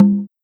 BONGO-CONGA119.wav